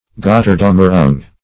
Gotterdammerung \Got`ter*dam"mer*ung\, Gotterdaummmerung